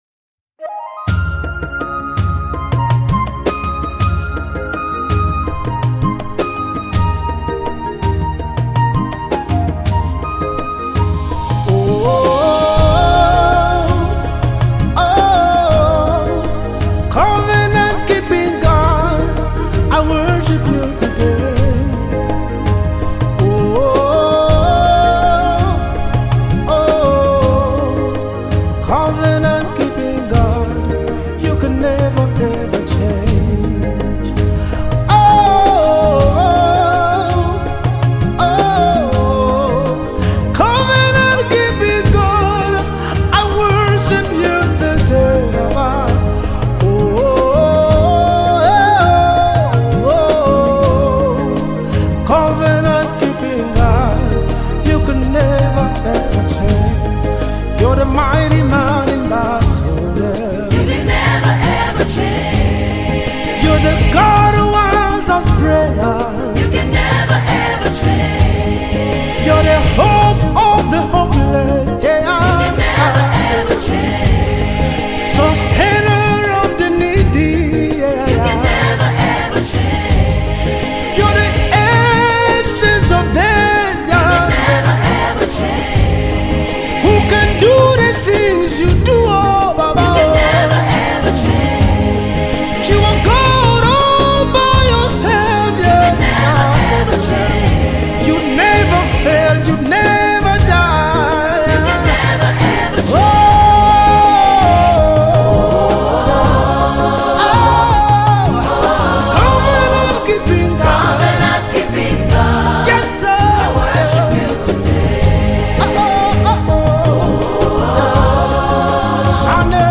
Multi award winning International Gospel minister
a song of delight and faith in God’s covenant and promises